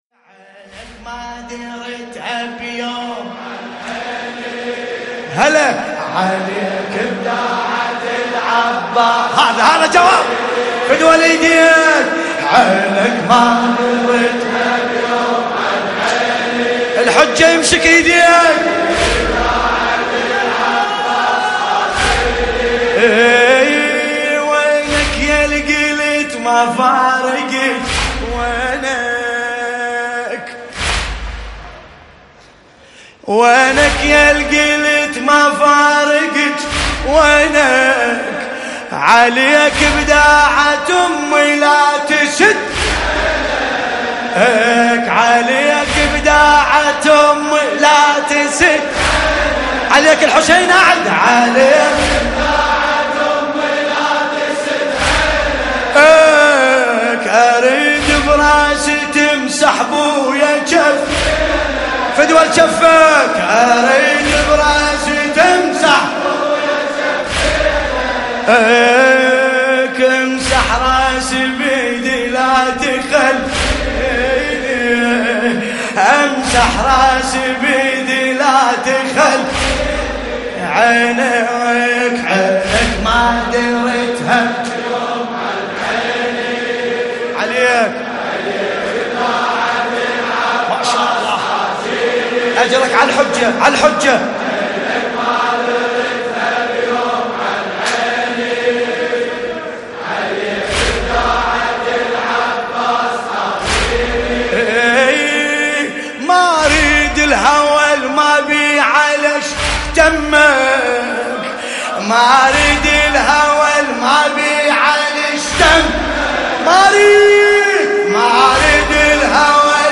قصيدة